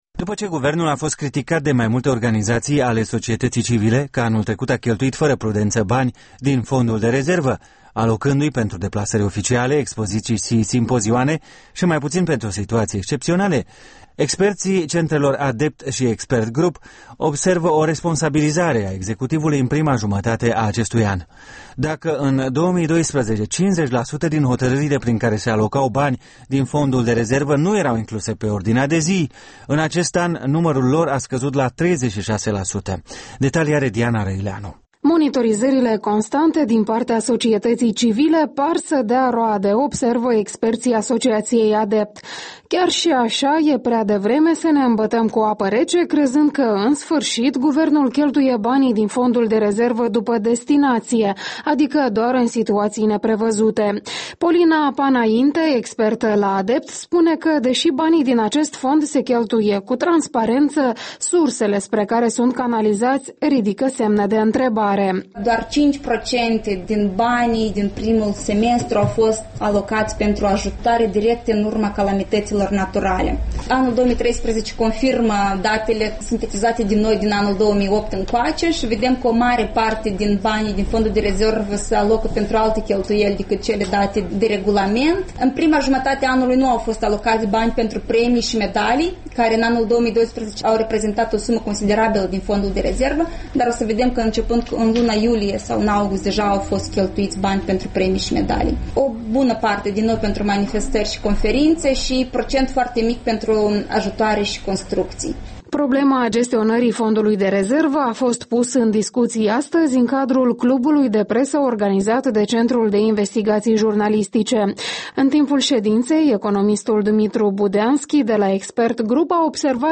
Rezervele Fondului de rezervă - o dezbatere lămuritoare